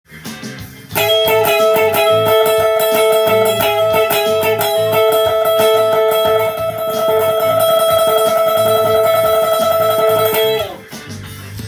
全てAmキーの曲で使えるフレーズになります。
スライドを取り入れた繰り返し和音フレーズです。
ポリリズム（変則的な音符の繰り返し）が入っています。